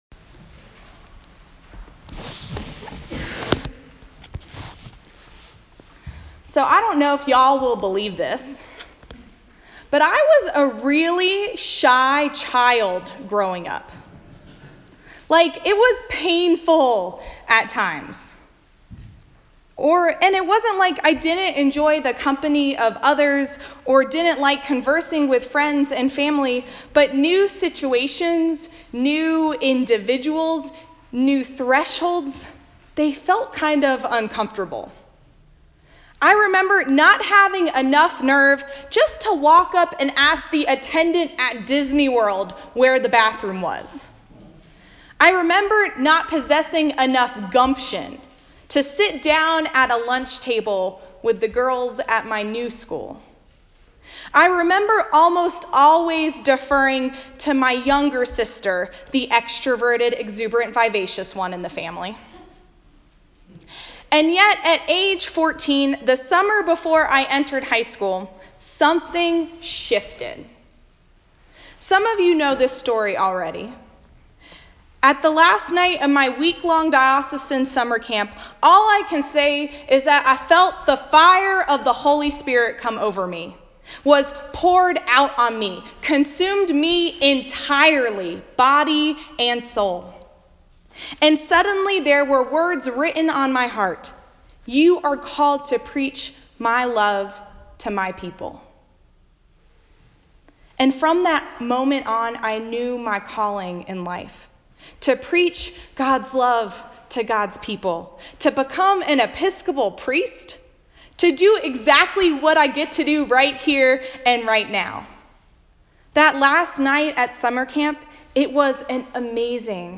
A Sermon for Pentecost Sunday, Year B